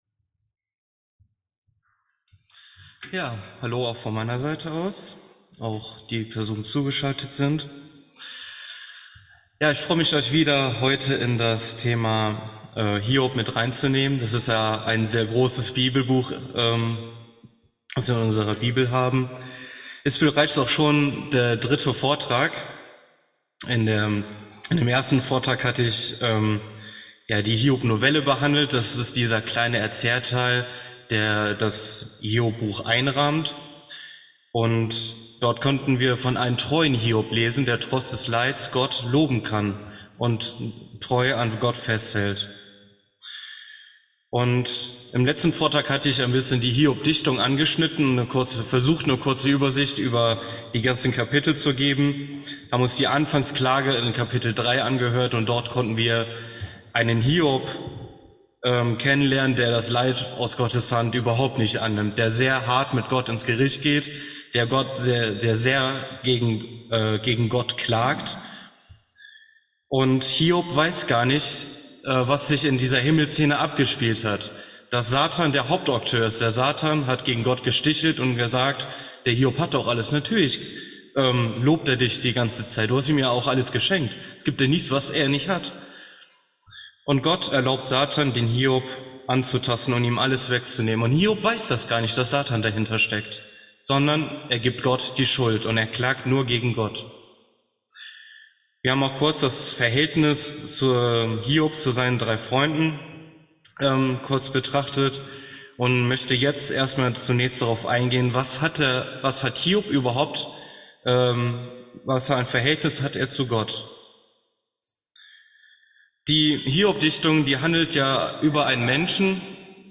Predigten August 2022